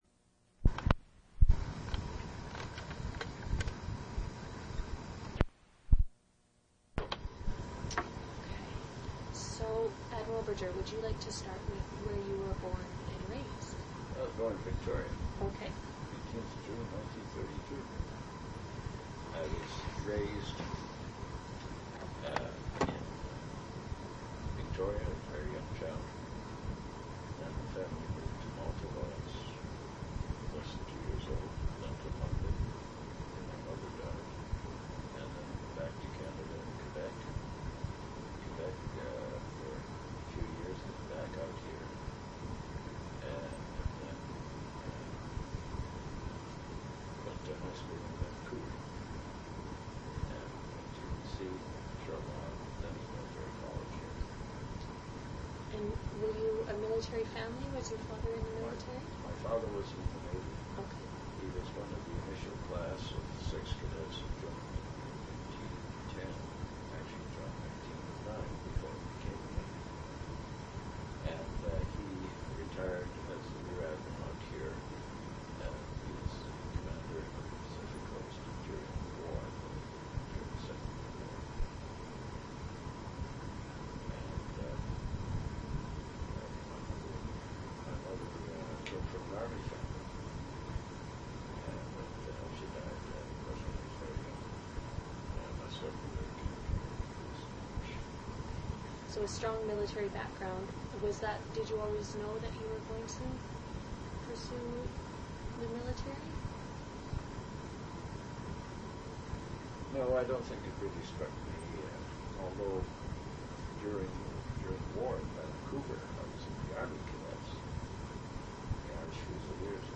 An interview/narrative of Admiral Nigel Brodeur's experiences as a weapons officer aboard the HMCS Kootenay in the early 1960s.
Original sound recording on audio cassette also available.